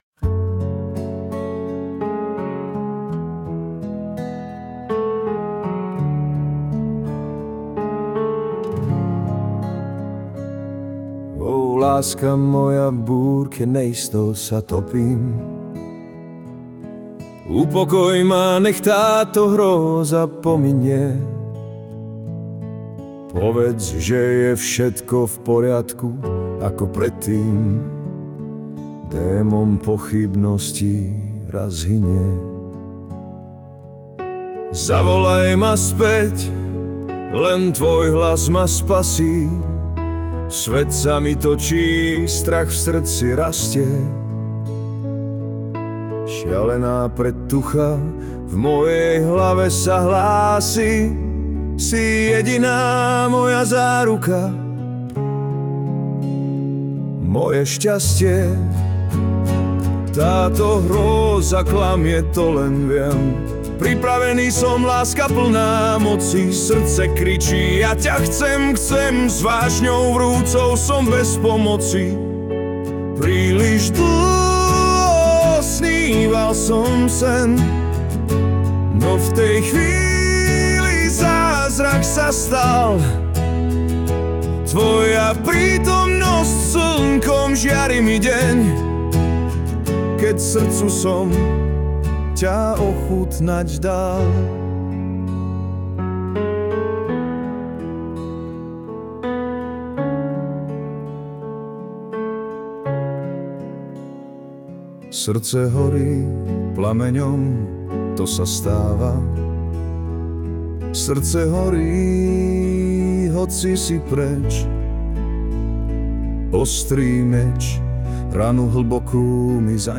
Hudb a aspev AI